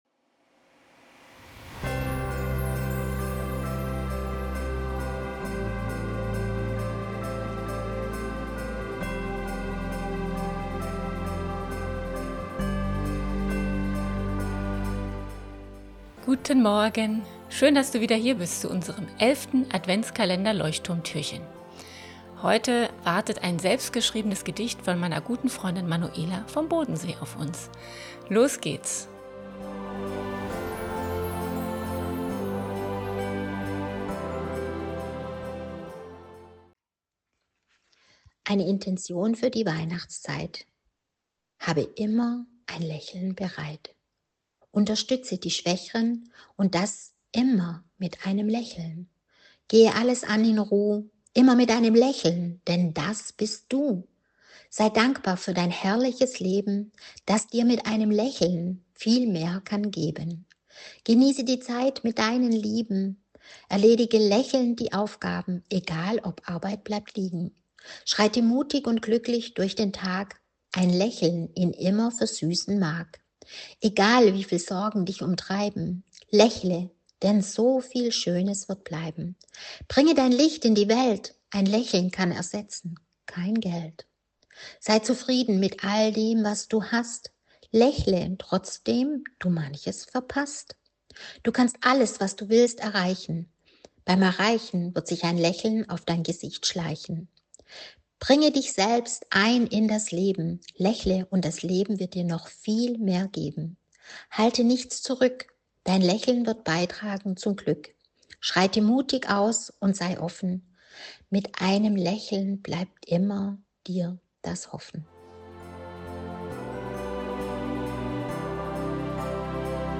Heute erwartet uns ein selbst geschriebenes Gedicht von meiner